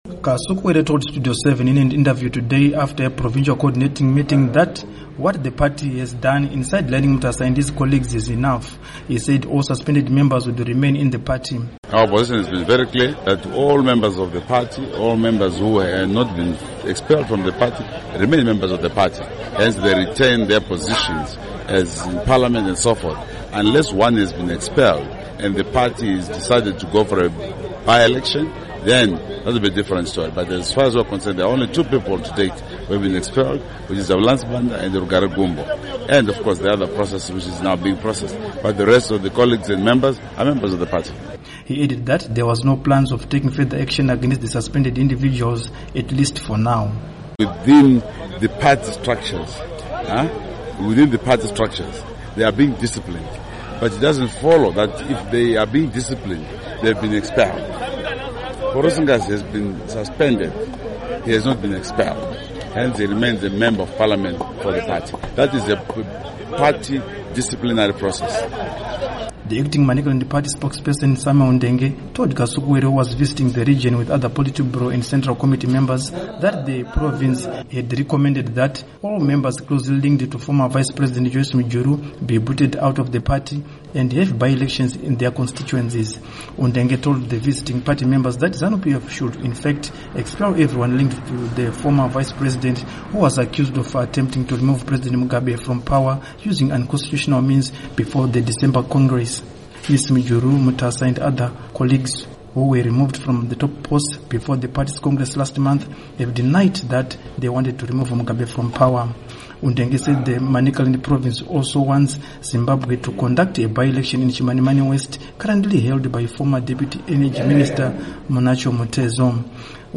Report on Zanu PF Squabbles